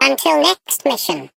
Sfx_tool_spypenguin_vo_exit_01.ogg